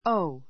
oh 小 óu オ ウ 間投詞 おお!